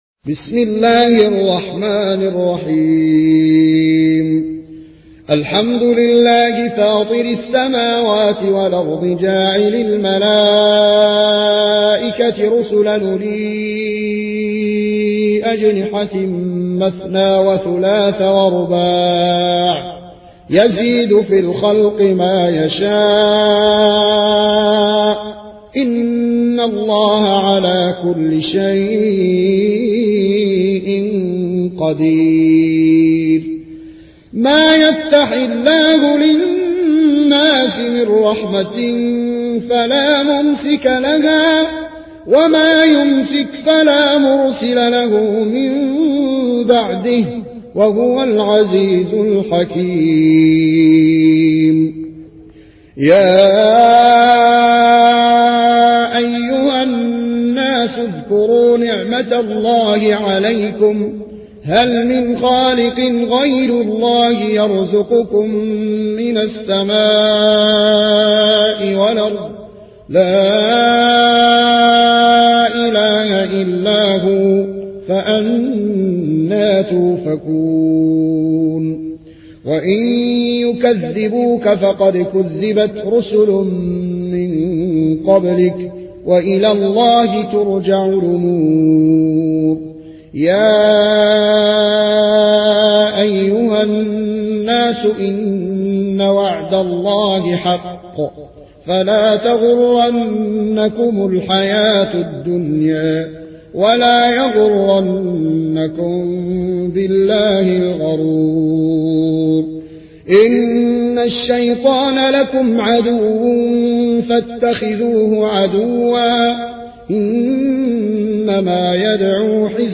روایت ورش از نافع